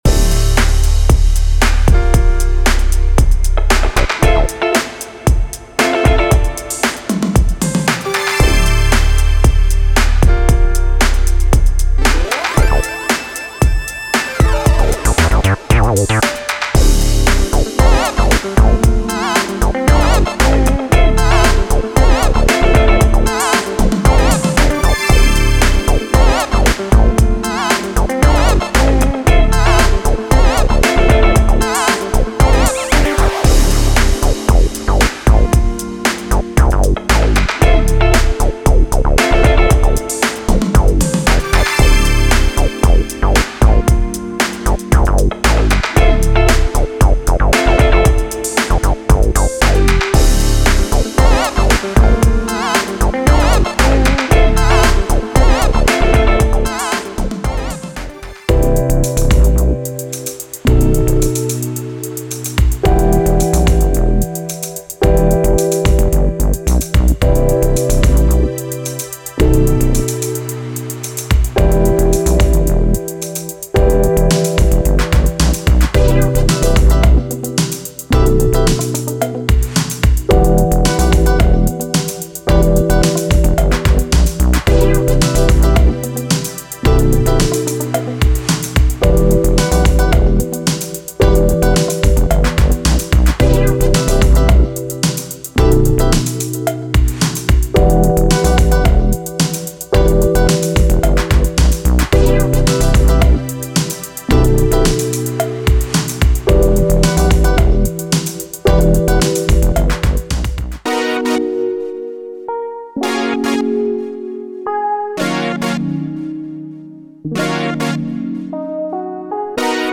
デモサウンドはコチラ↓
Genre:Funk
110, 114, 115, 117 BPM